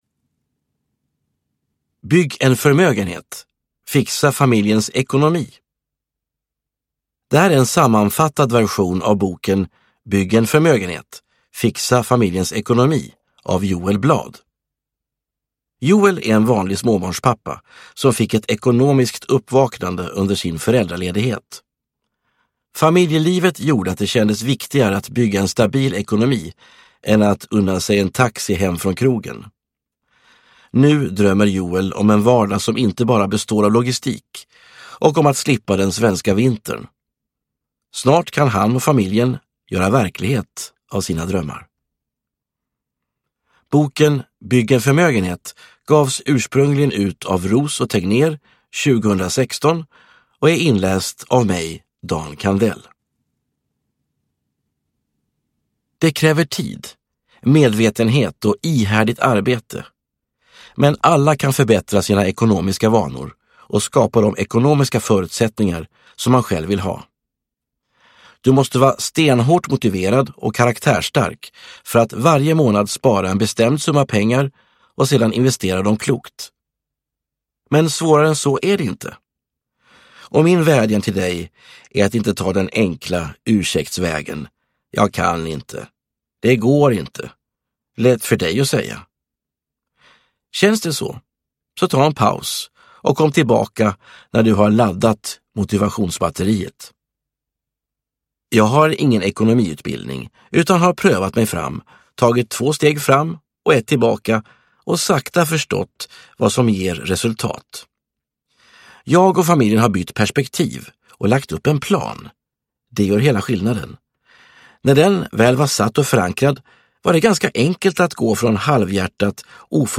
Bygg en förmögenhet – Fixa familjens ekonomi – Ljudbok – Laddas ner